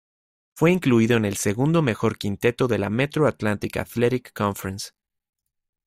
Tariamas kaip (IPA) /seˈɡundo/